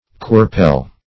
Search Result for " quirpele" : The Collaborative International Dictionary of English v.0.48: Quirpele \Quir"pele\ (kw[~e]r"p[=e]l), n. [Tamil k[imac]rippi[lsdot][lsdot]ai.]